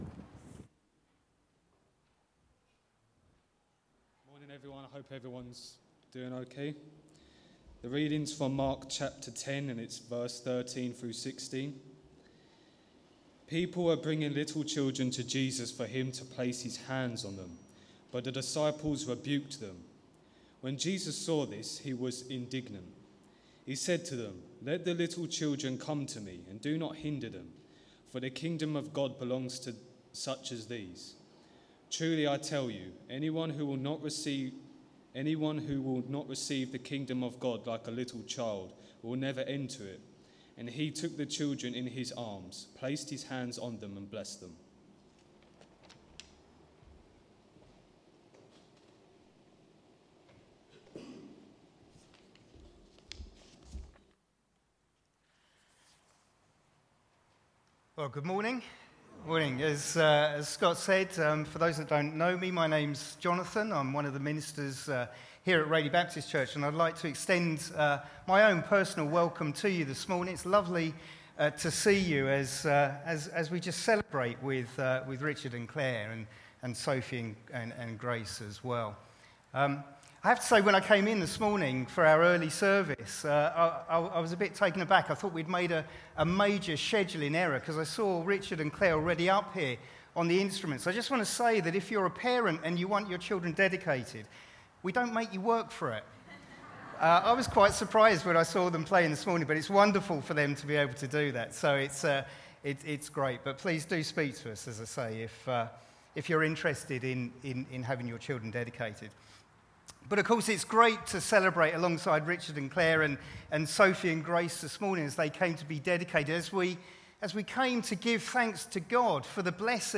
A message from the series "Mark10 v13-16."